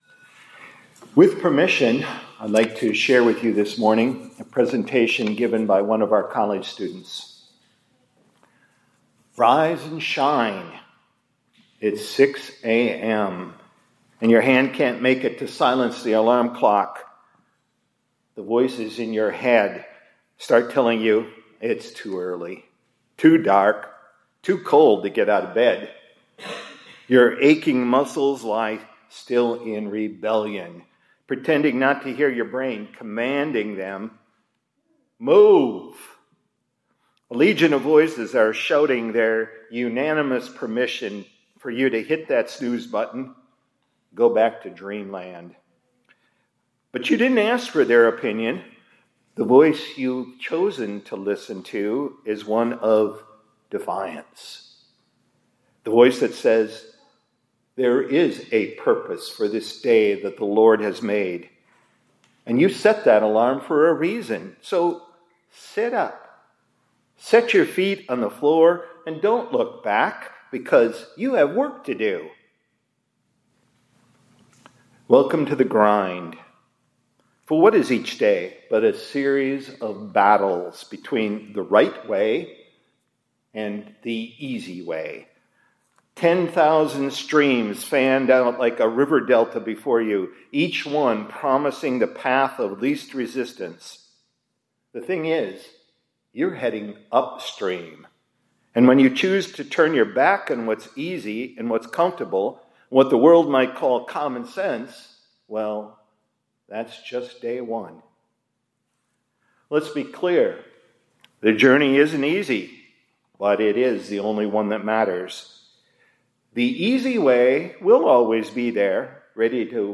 2025-09-09 ILC Chapel — Rise and Shine